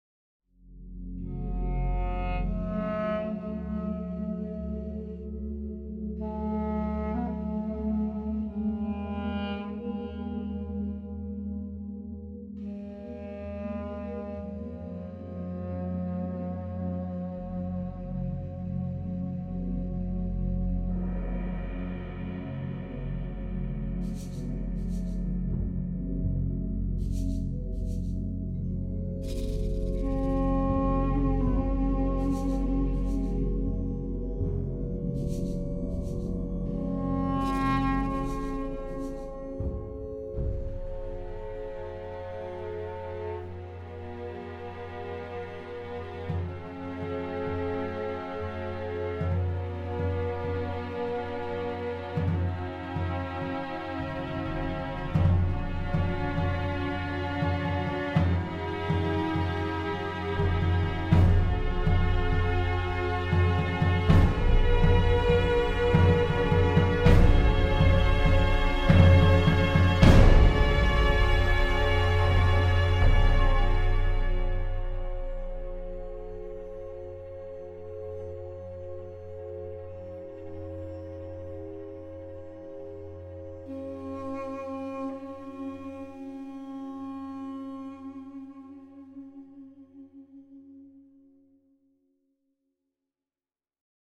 MIDI orchestration